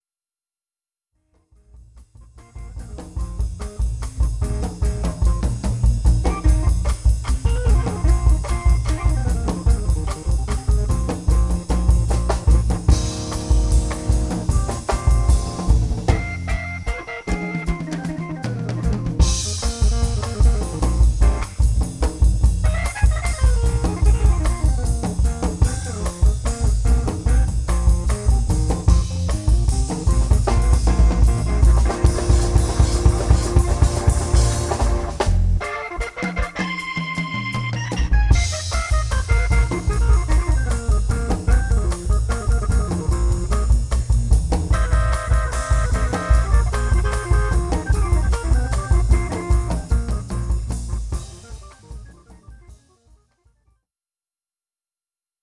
I've used yamin on the tutorial example a bit like the tutorial with the ardour audio multitrack program and have come up with this
which sounds hip-ish and at least a bit more interesting then the unprocessed version, which maybe isn't needed for the music, but why not.